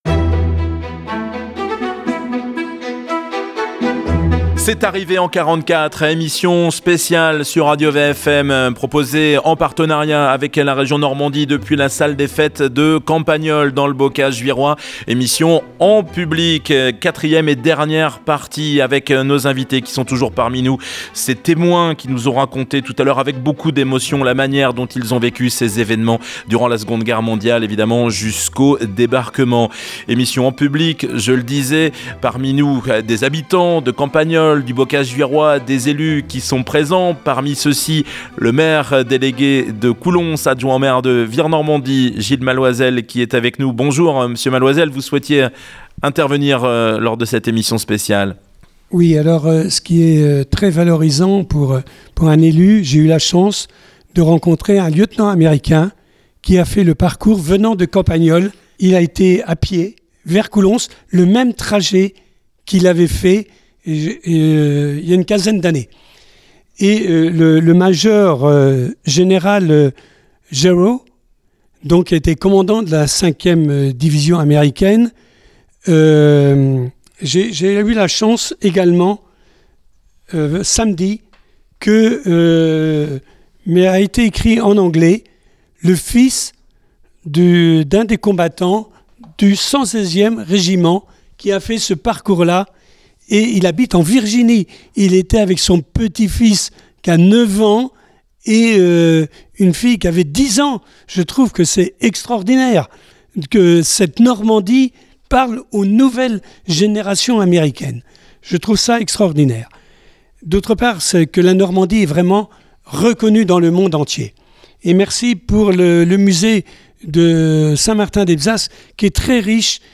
Témoignages, récits de la libération du Bocage. Un rendez-vous en partenariat avec la Région Normandie dans le cadre du label 80ème anniversaire du Débarquement et de la Bataille de Normandie .